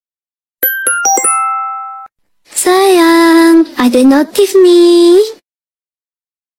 Genre: Nada dering imut